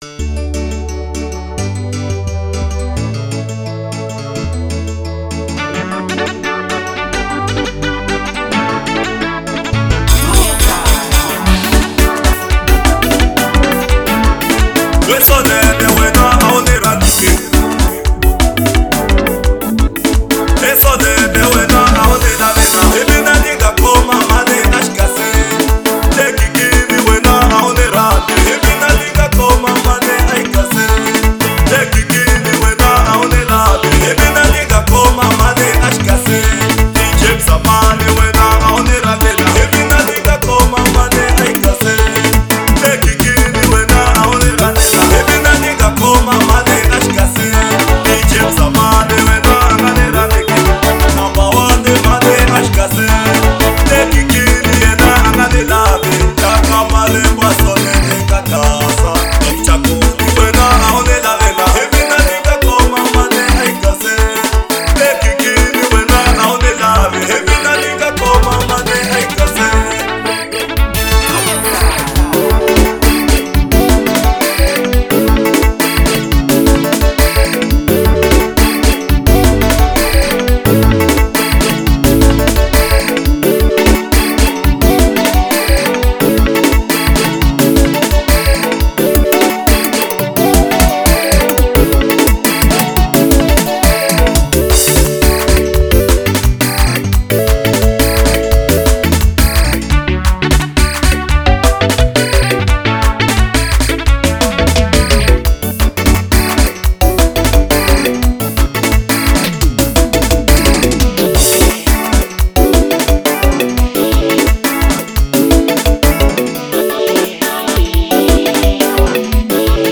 05:13 Genre : Xitsonga Size